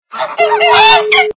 При прослушивании Звуки - Утка кря-кря качество понижено и присутствуют гудки.
Звук Звуки - Утка кря-кря